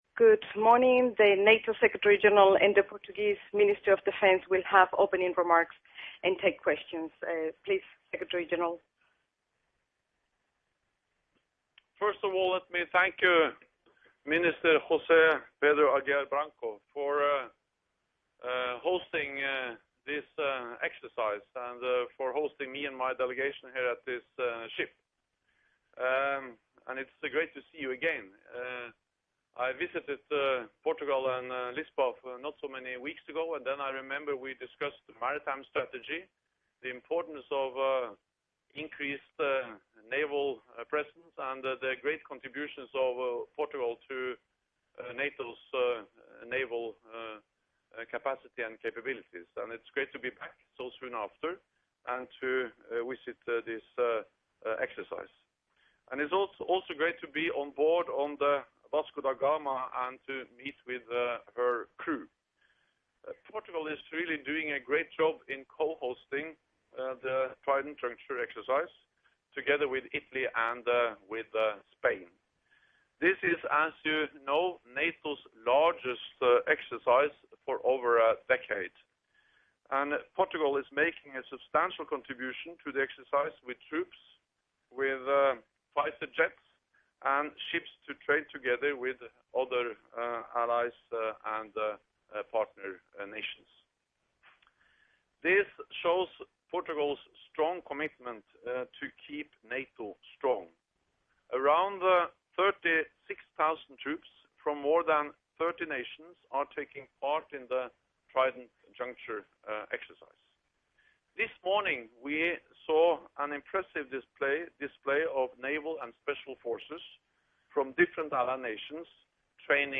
Joint press conference with NATO Secretary General Jens Stoltenberg and Portuguese Defence Minister José Pedro Aguiar Branco, on board Frigate Vasco de Gama